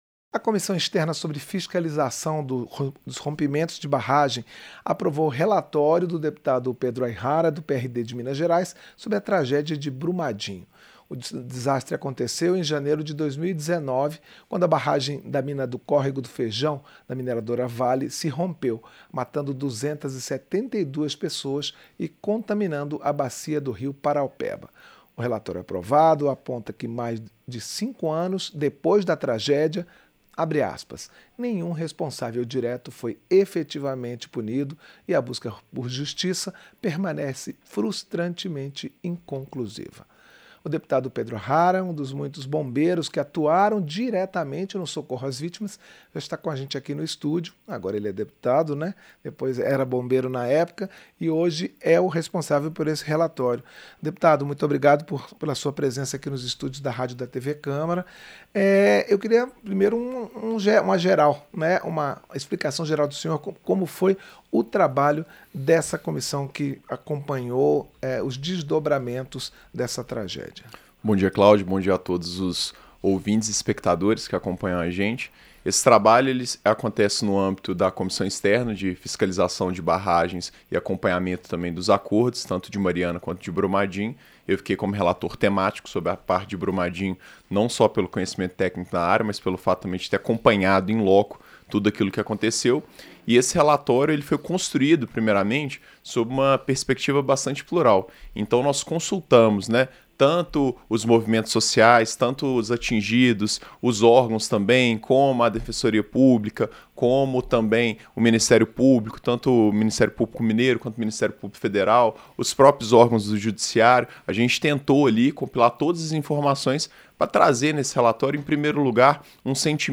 Entrevista - Dep. Pedro Aihara (PRD-MG)